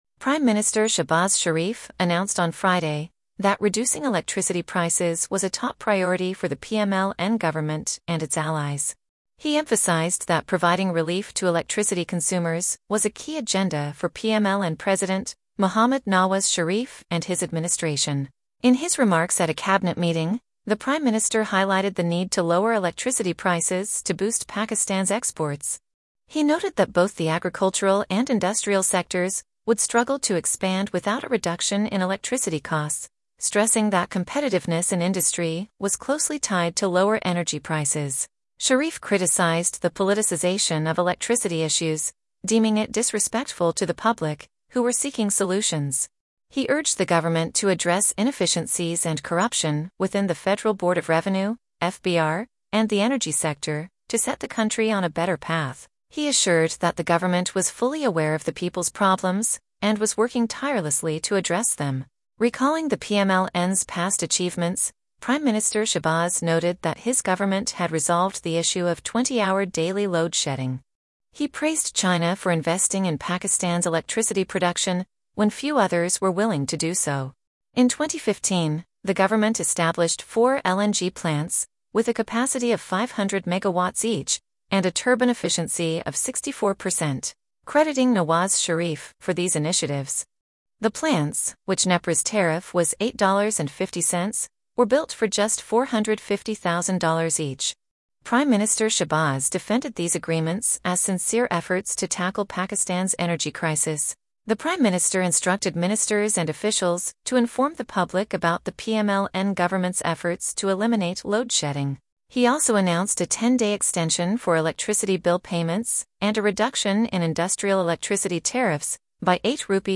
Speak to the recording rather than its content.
In his remarks at a Cabinet meeting, the Prime Minister highlighted the need to lower electricity prices to boost Pakistan’s exports.